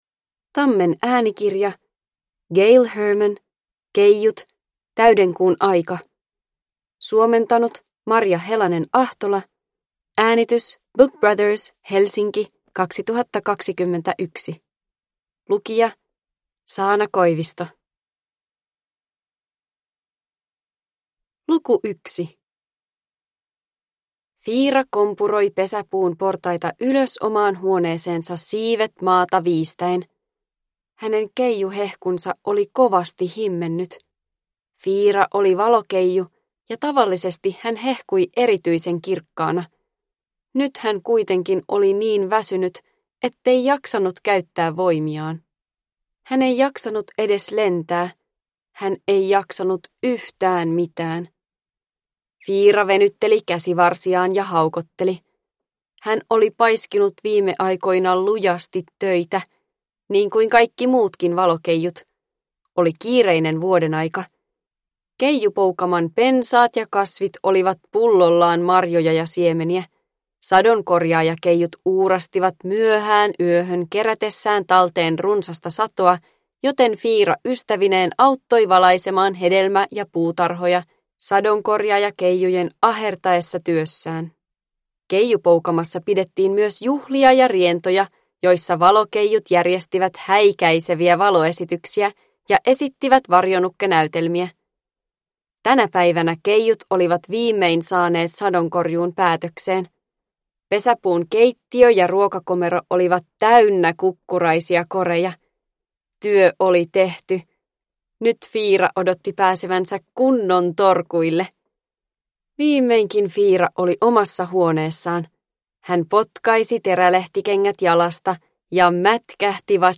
Keijut. Täydenkuun aika – Ljudbok – Laddas ner